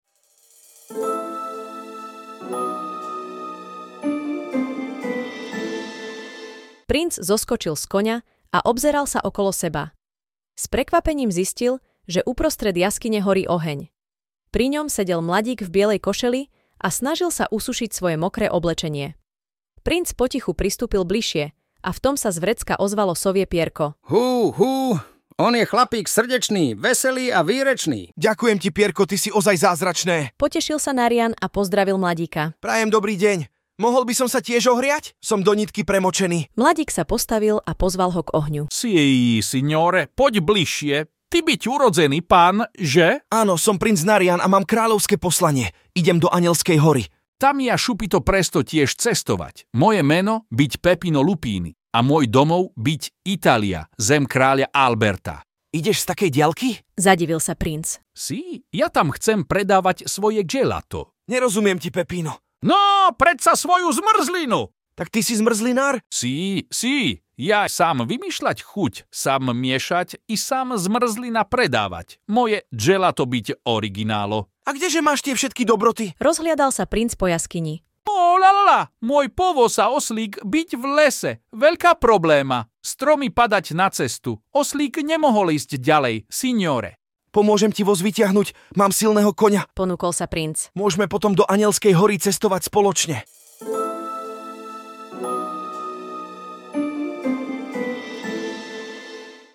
Možno vás trochu prekvapia nezvyčajné hlasy, či intonácia. Využili sme služby umelej inteligencie pre lepšiu predstavivosť, ale určite nám dáte za pravdu, že skutočný hlas mamičky, otecka či starej mamy to nenahradí.